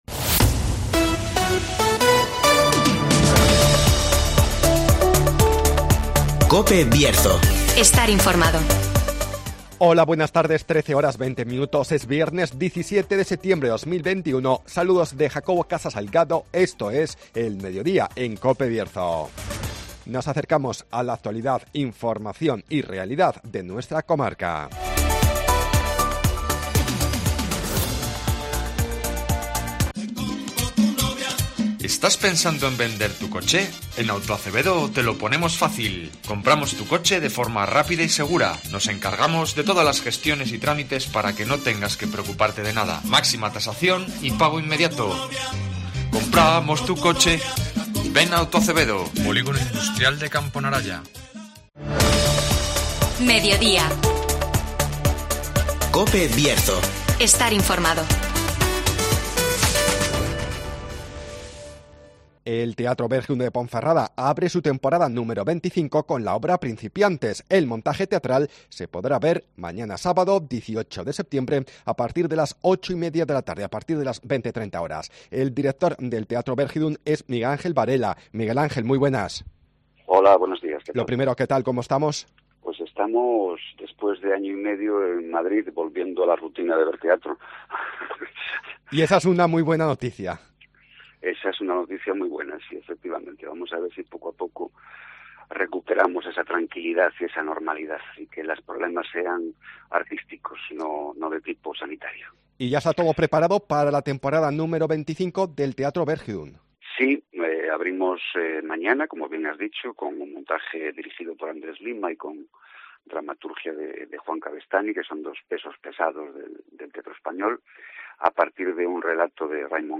Principantes abre la temporada 25 del Bergidum de Ponferrada (Entrevista